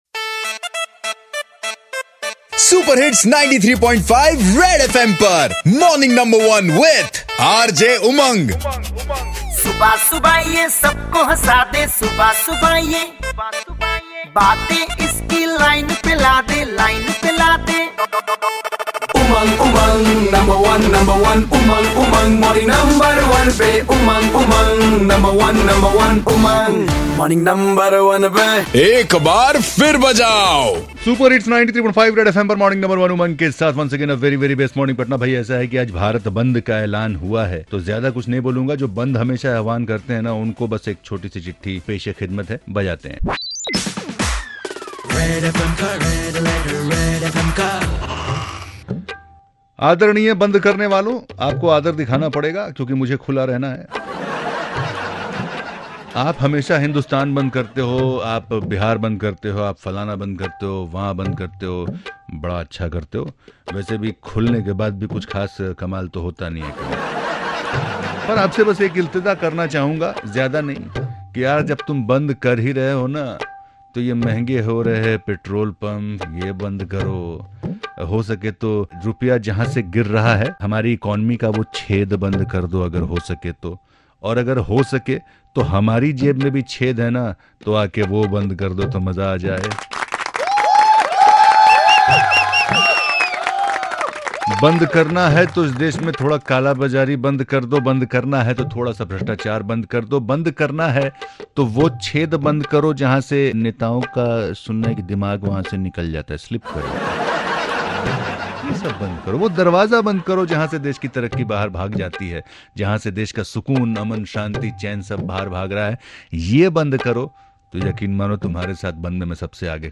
reads out a Red Letter for some other areas that need to be focused on and called for a Bandh!